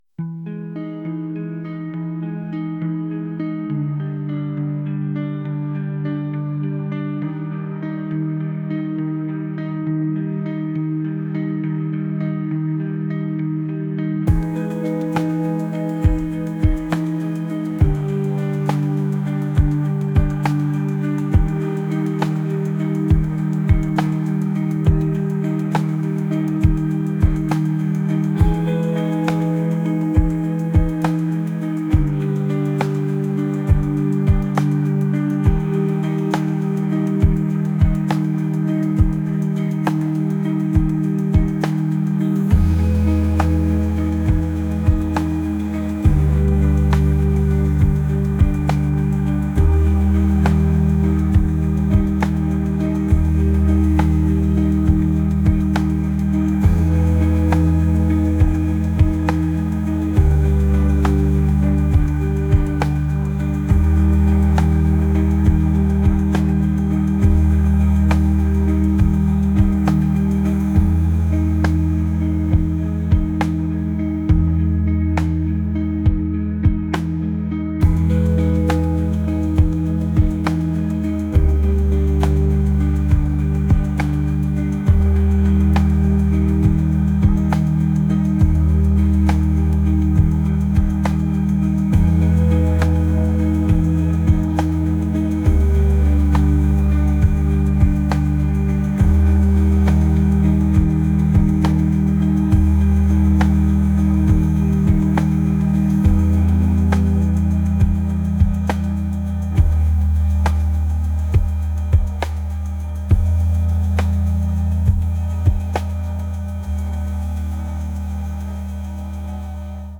indie | pop | ambient